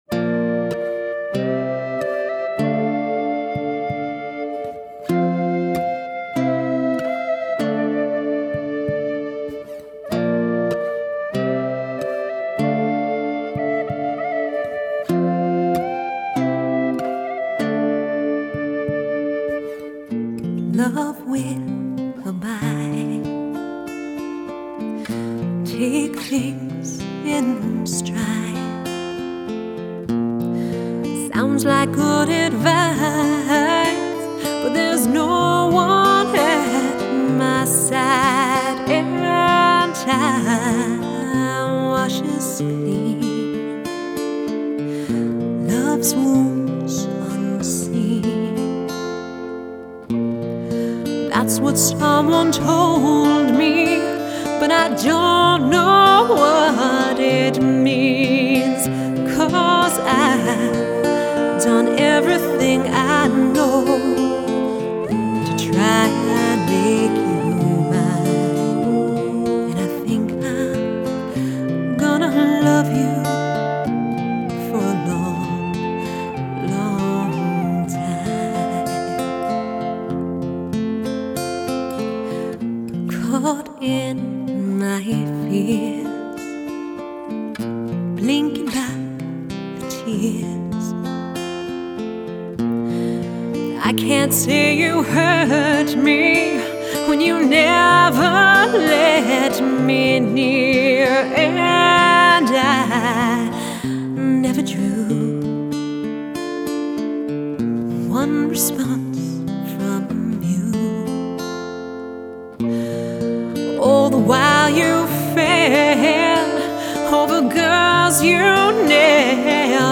Genre: Folk Rock, Celtic, Medieval